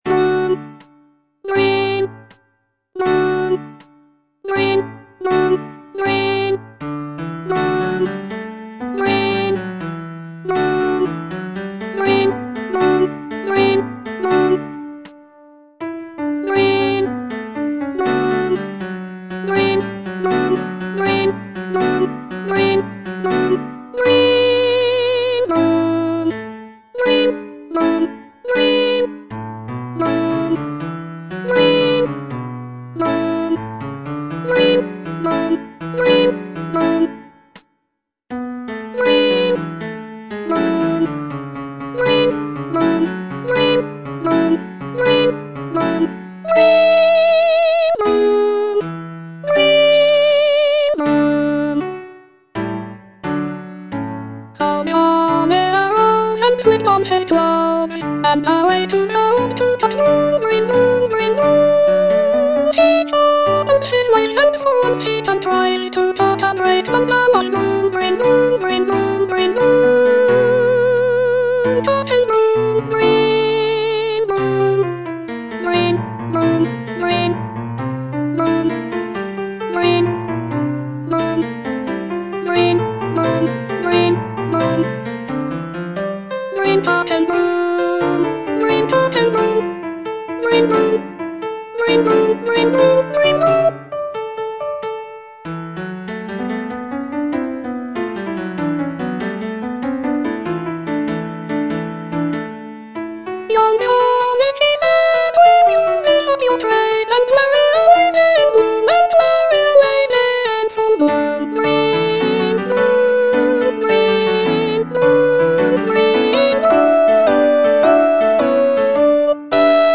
Soprano II (Voice with metronome)